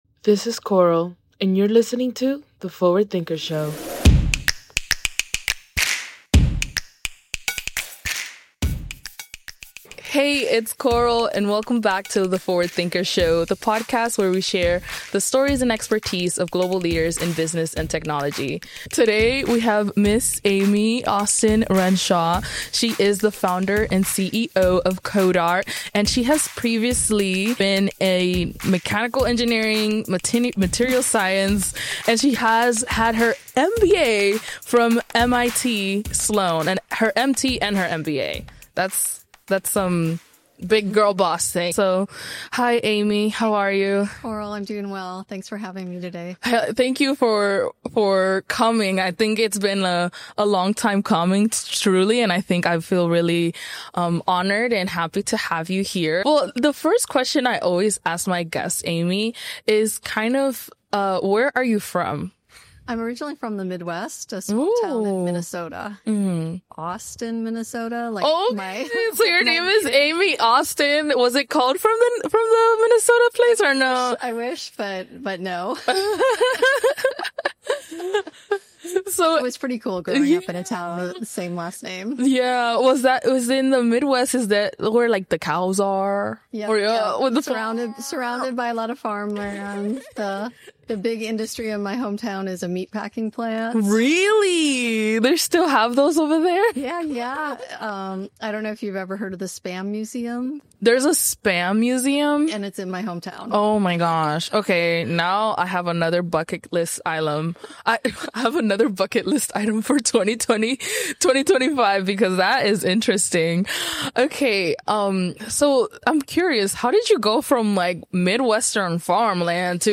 Featuring conversations with forward-thinking leaders from diverse backgrounds, the podcast captures unique insights and groundbreaking ideas.